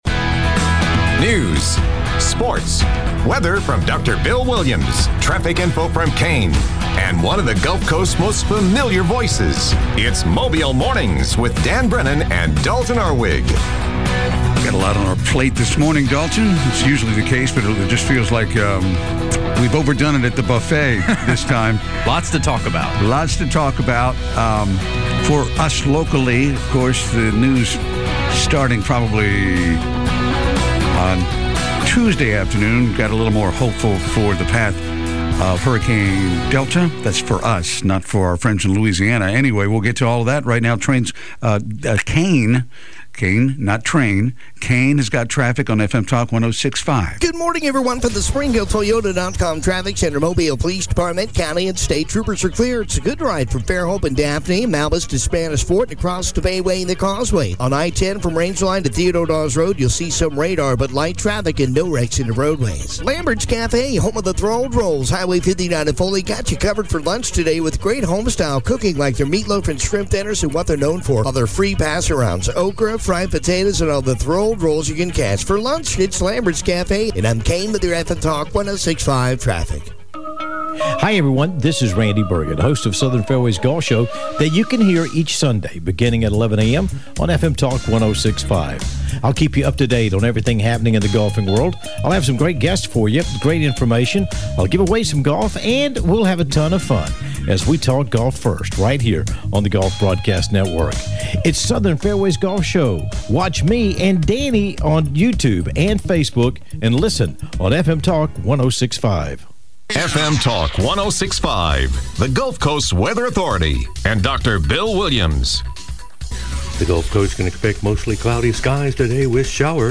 report on local news and sports
reports on traffic conditions. Other subjects include Covid 19 Stats, and The Jeff Poor Show.